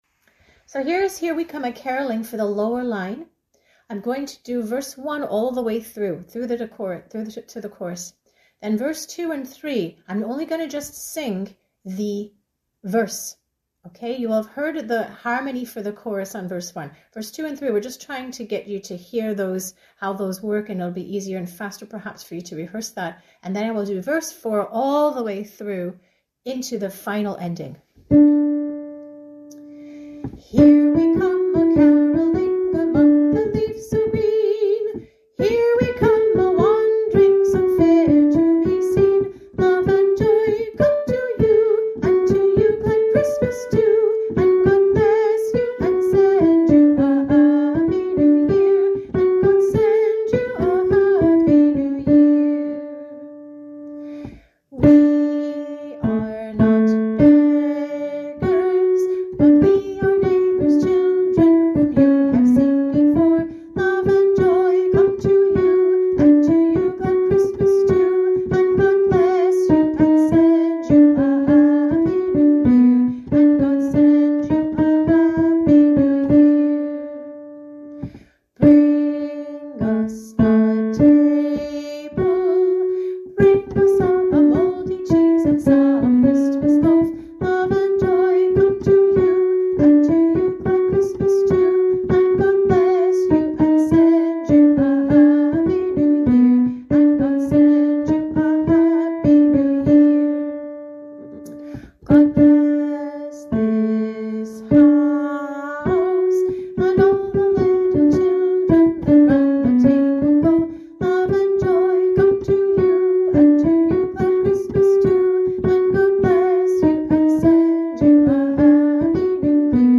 here-we-come-harmony-line.mp3